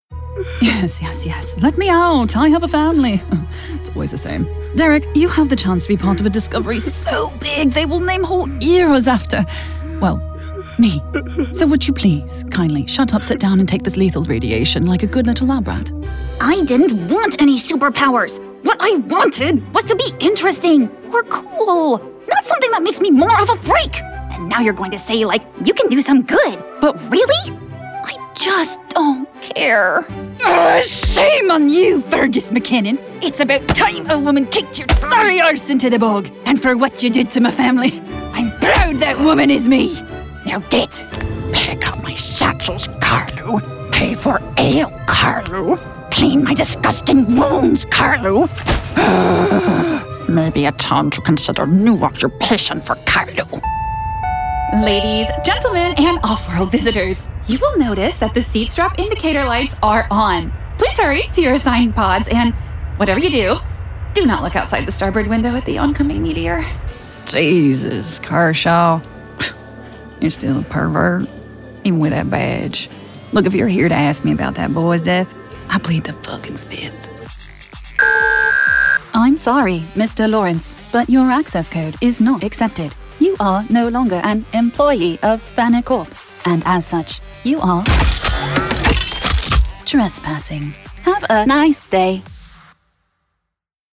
Character Demo